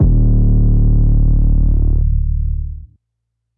Major 808 4.wav